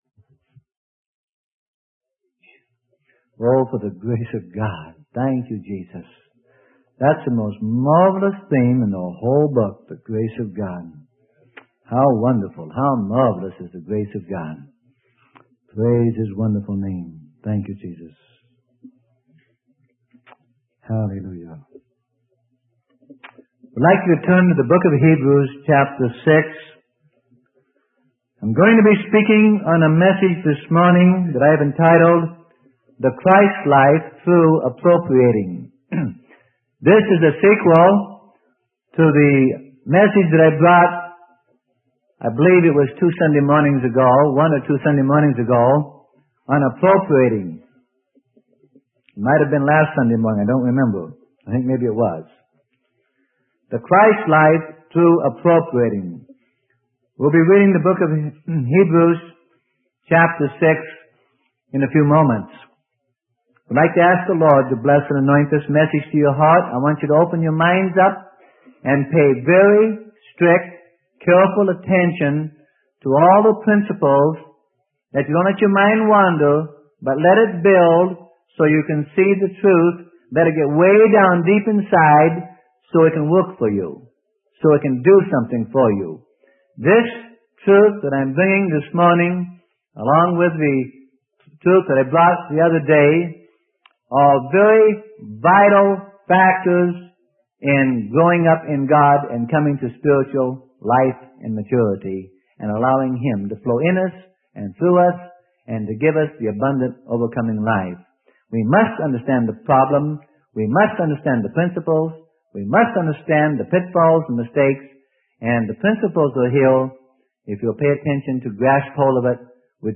Sermon: The Christ Life Through Appropriating - Freely Given Online Library